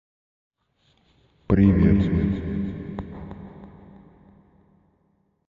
Привет (с эффектом эхо)
• Категория: Привет(приветствие)
На этой странице вы можете прослушать звук привет (с эффектом эхо).